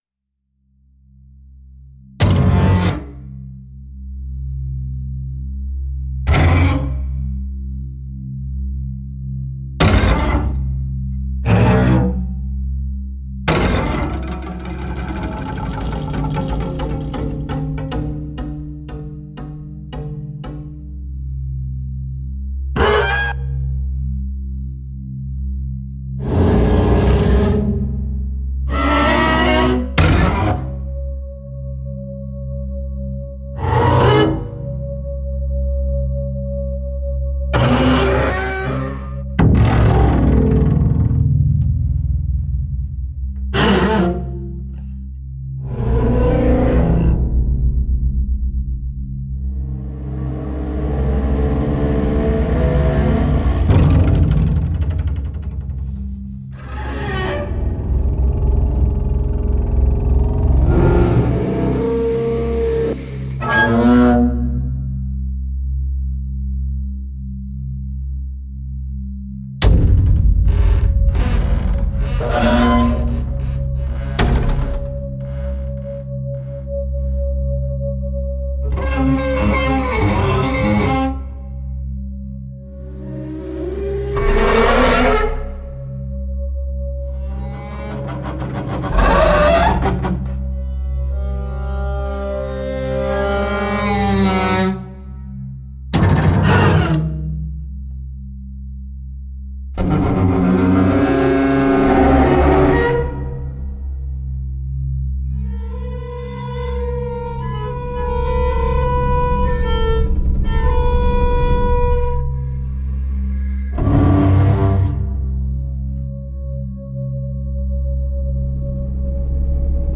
【低音提琴乐器KONTAKT扩展】SampleTraxx – Double Bass Essentials
这种以其深沉浑厚的音色而闻名的乐器，在这里被重新演绎，呈现出一种令人震撼的音色
准备好迎接一场由奇异的弓法、尖锐的泛音、诡异的琴弦刮擦声、迷幻的打击乐、低沉的渐强和令人毛骨悚然的颤音组成的交响乐吧。
整体音色尽可能保持干涩，以保留乐器粗犷原始的特质。
Double Bass Hard / Soft Hit
Pizzicato